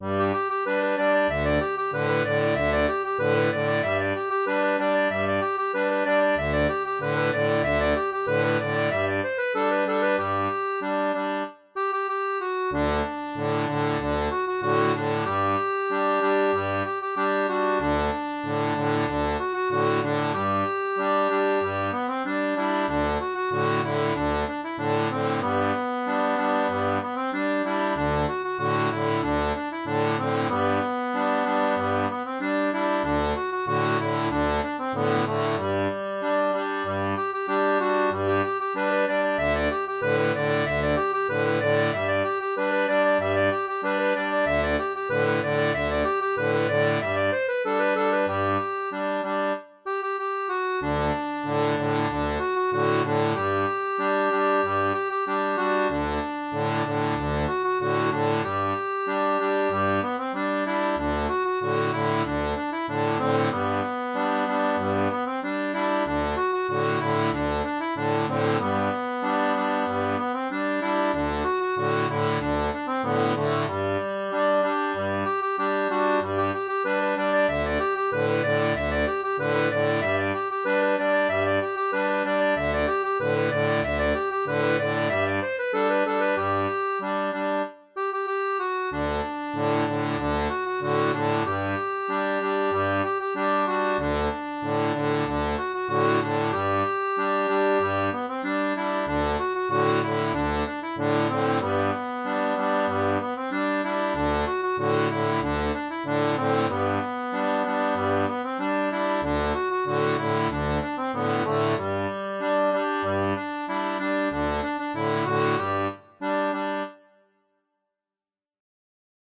Air traditionnel portugais
Folk et Traditionnel